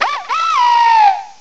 cry_not_dartrix.aif